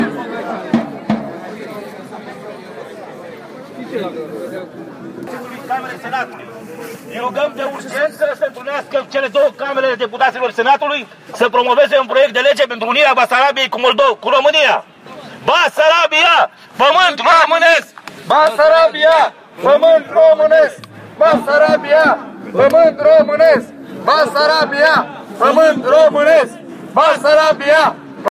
Voce unionist București 22 oct